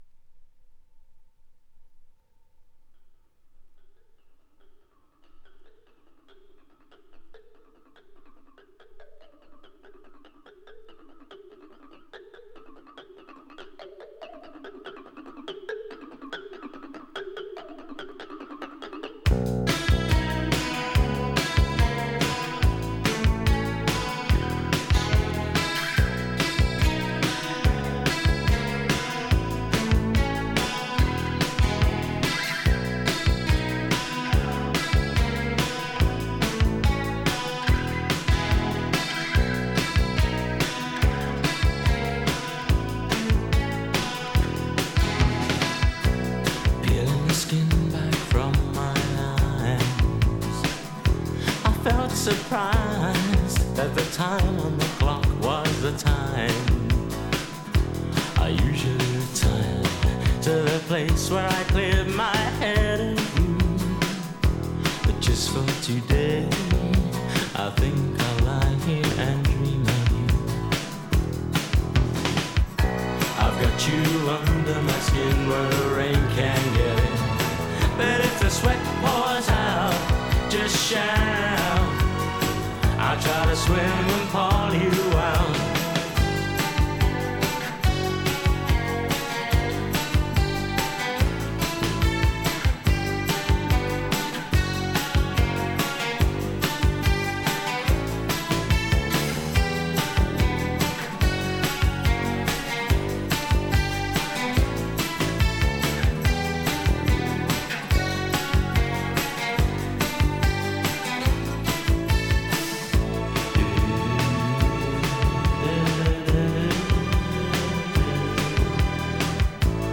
It’s a song in two halves.